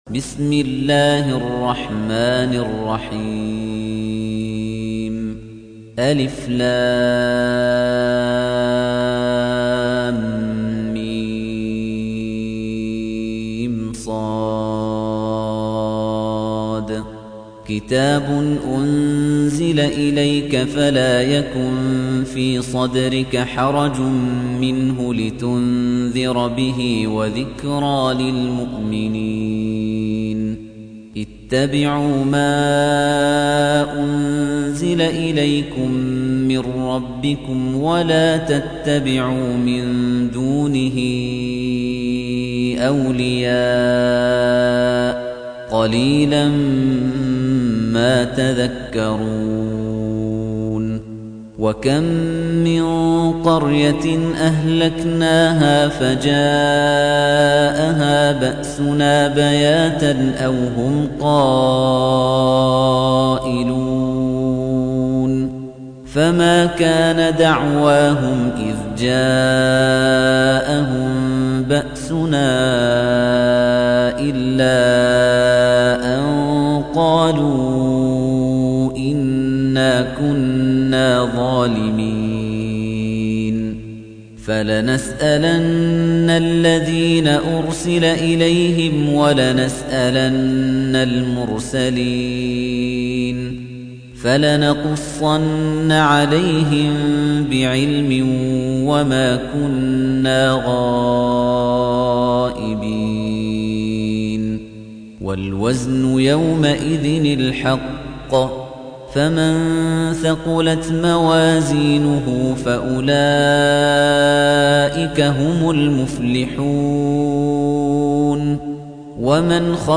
تحميل : 7. سورة الأعراف / القارئ خليفة الطنيجي / القرآن الكريم / موقع يا حسين